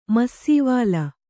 ♪ massīvāla